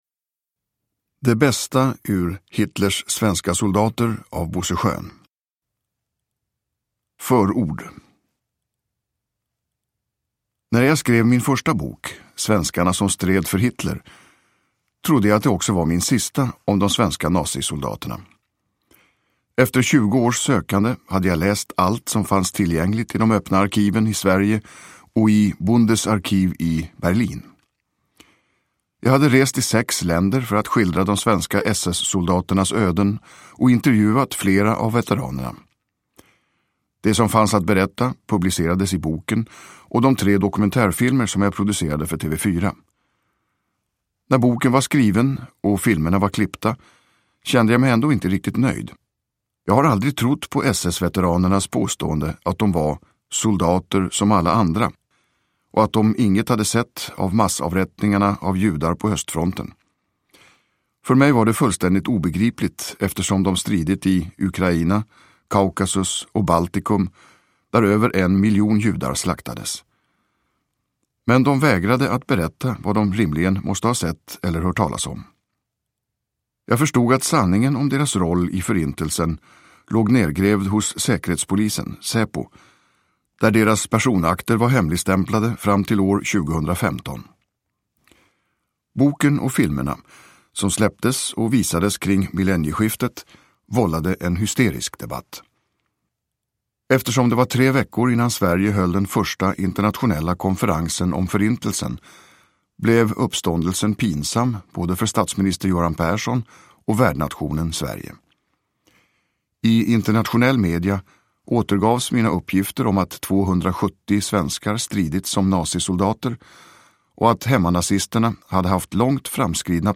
Hitlers svenska soldater – Ljudbok – Laddas ner
Uppläsare: Tomas Bolme